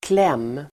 Uttal: [klem:]